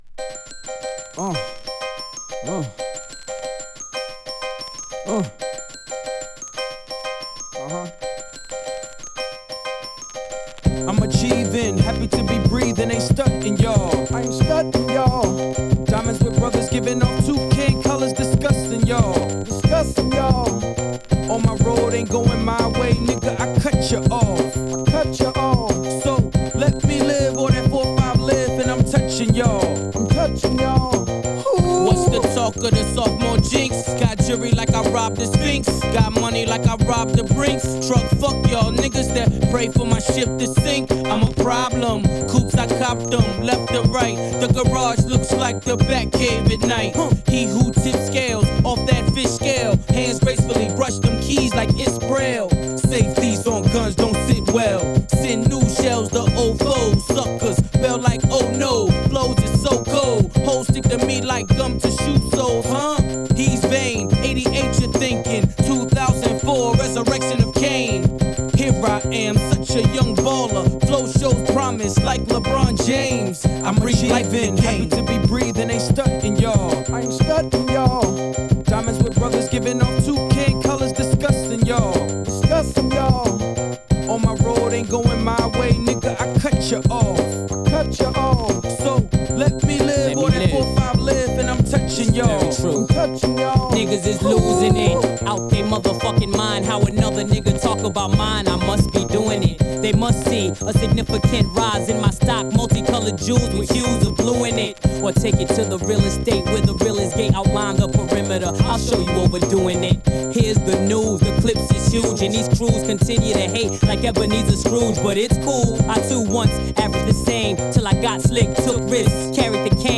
スモーキー・チューンで攻めまくり！